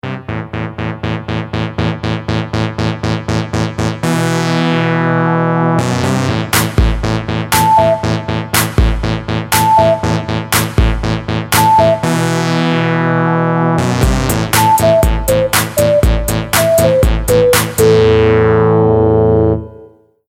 | electro hip hop |